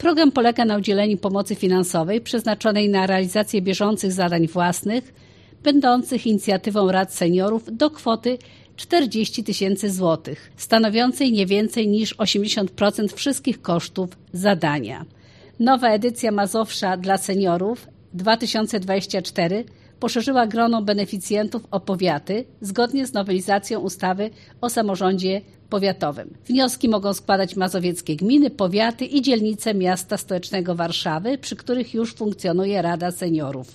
O programie mówi Elżbieta Lanc, członkini zarządu województwa mazowieckiego: